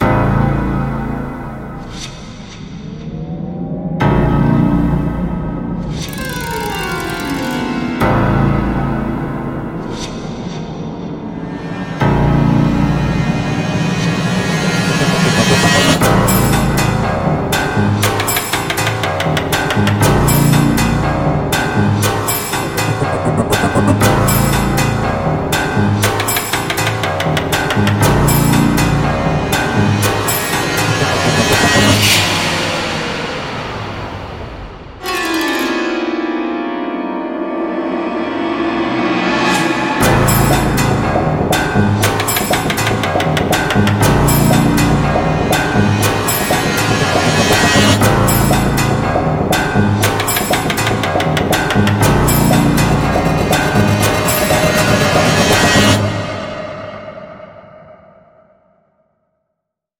这并非传统纯净的三角钢琴音色库；而是一款独具特色、个性鲜明的 Kontakt 音色库，旨在突破钢琴音色的界限
无论您是为恐怖电影配乐、创作实验性音景，还是寻求独特的音色元素，这款音色库都将带您领略钢琴原始而狂野的一面